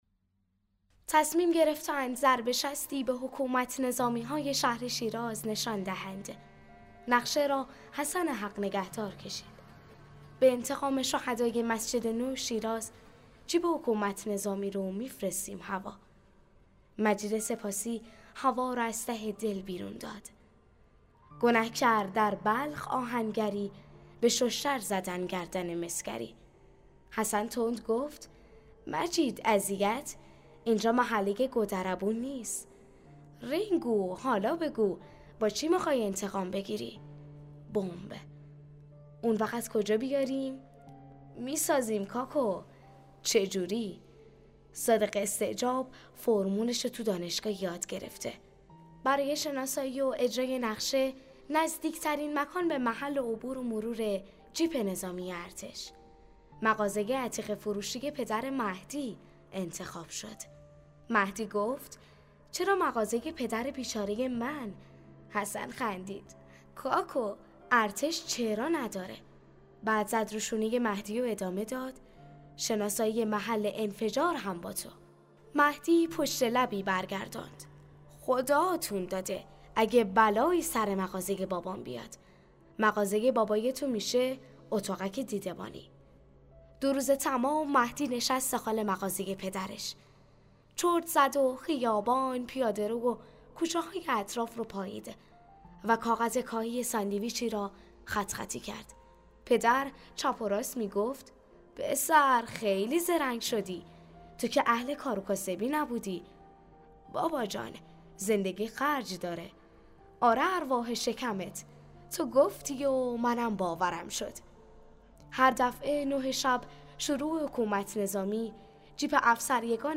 کتاب صوتی/ «لندکروز بهشت» بخش اول